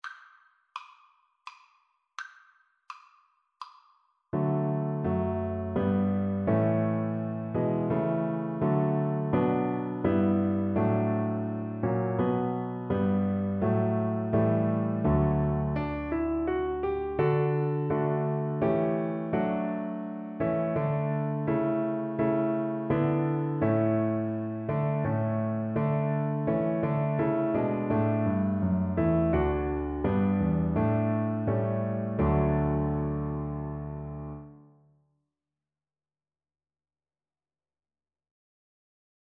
Cello
3/4 (View more 3/4 Music)
D major (Sounding Pitch) (View more D major Music for Cello )
Traditional (View more Traditional Cello Music)